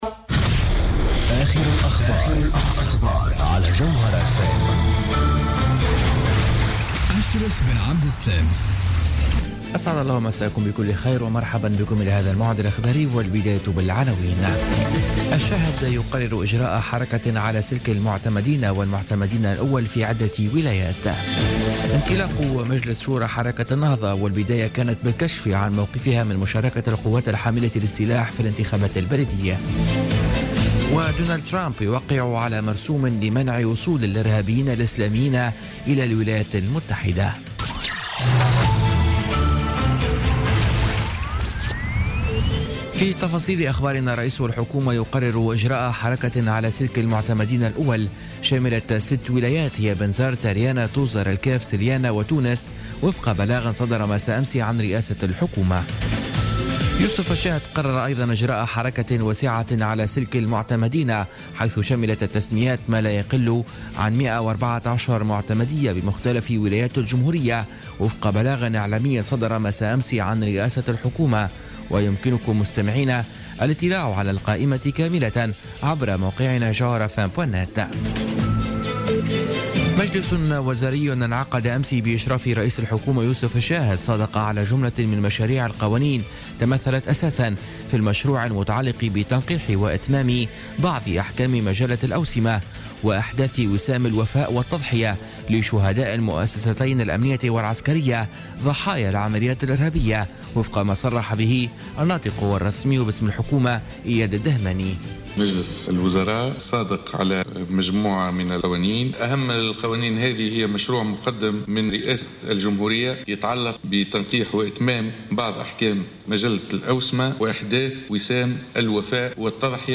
نشرة أخبار منتصف الليل ليوم السبت 28 جانفي 2017